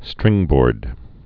(strĭngbôrd)